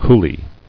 [cou·lee]